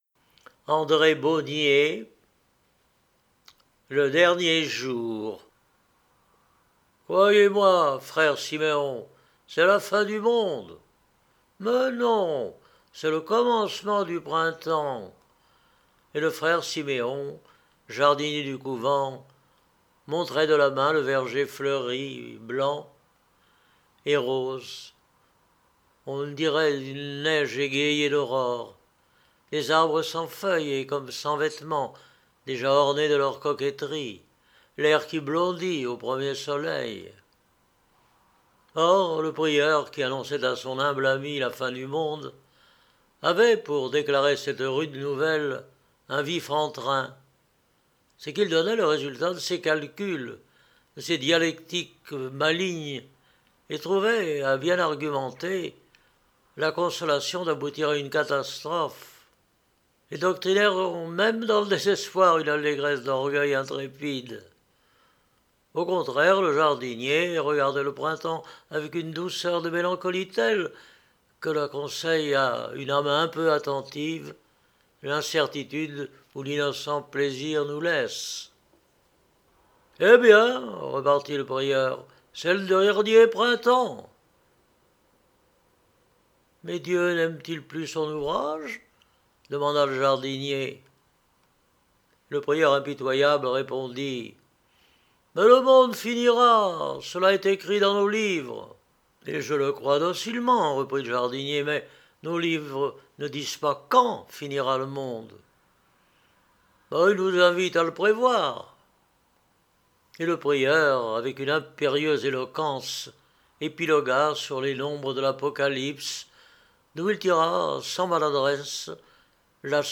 BEAUNIER André – Livres Audio !
Genre : Romans Les ouvrages de Jules Verne